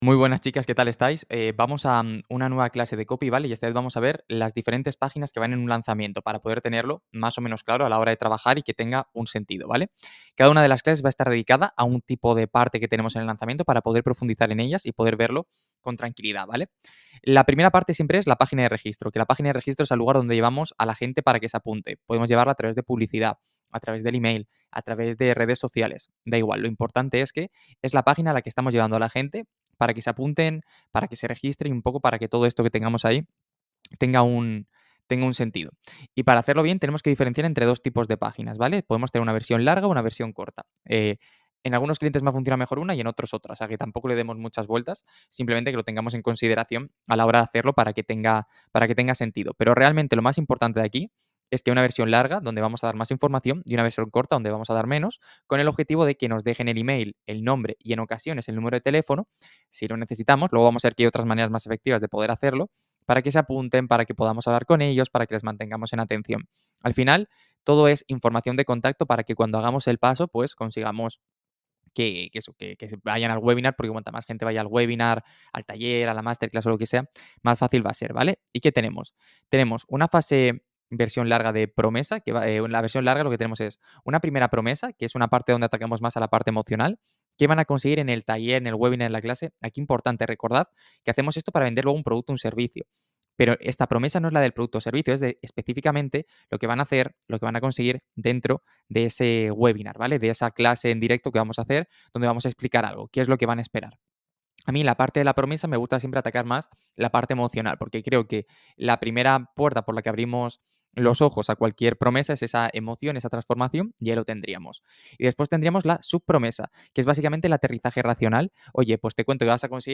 ¿Qué landings has de tener en cuenta cuando estás pensando tu próximo lanzamiento? Lo analizamos en directo hoy.